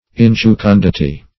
Search Result for " injucundity" : The Collaborative International Dictionary of English v.0.48: Injucundity \In`ju*cun"di*ty\, n. [L. injucunditas.